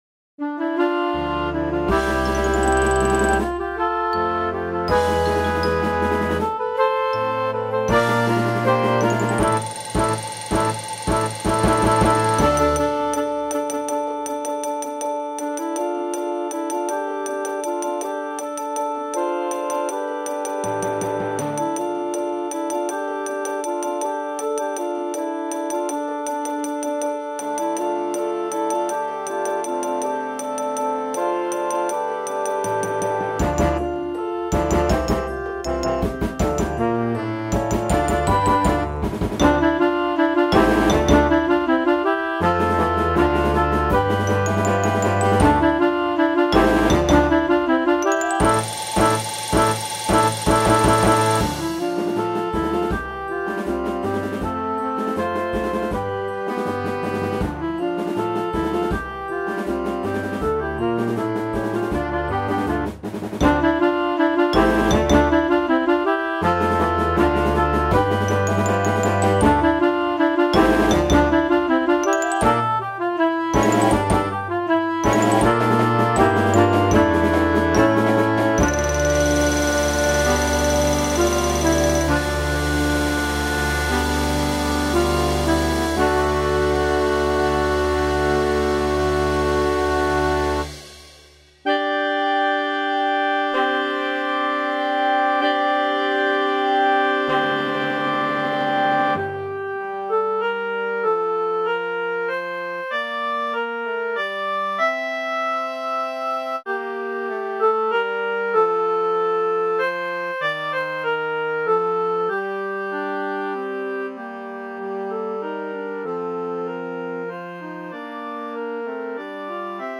Gattung: Flexible Besetzung
Besetzung: Blasorchester